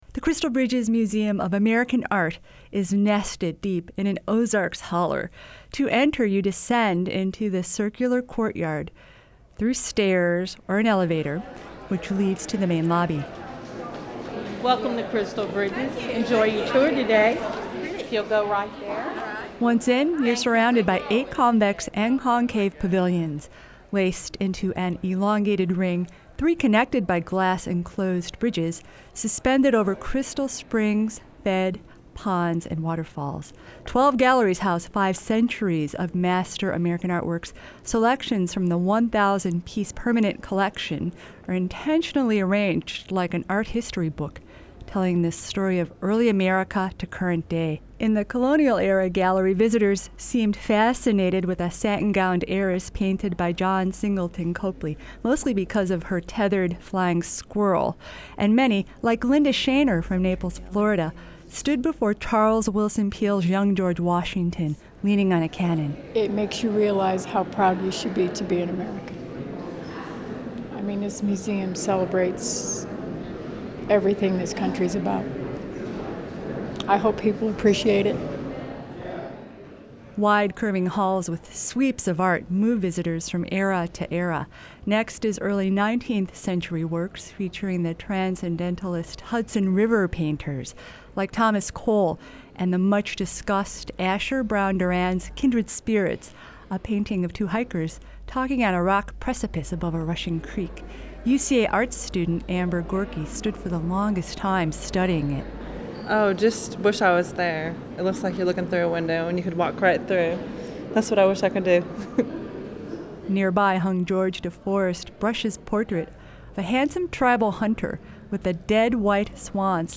One 11.11.11, we roamed the galleries of Crystal Bridges Museum of American Art taping reaction from locals, volunteers, visitors, staff and a lead acquisitions curator.
CB_Museum_Reactions.mp3